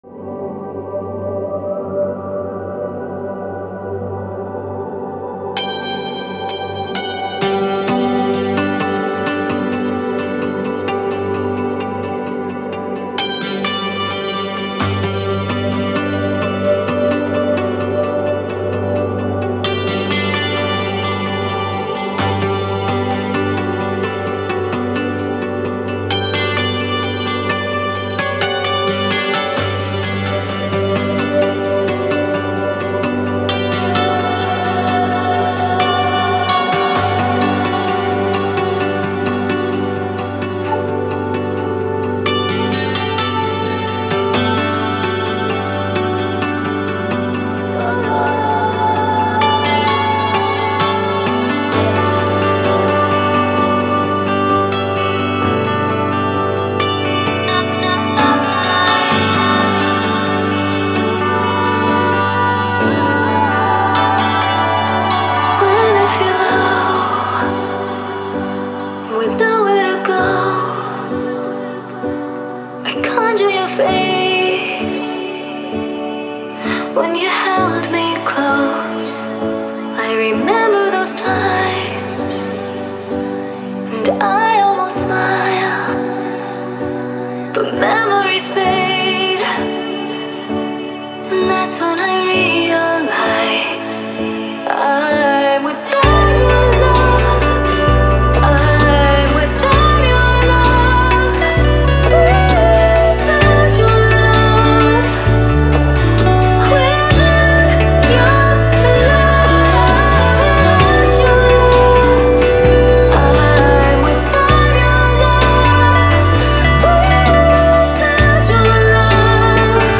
Стиль: Chillout / Lounge Ambient / Downtempo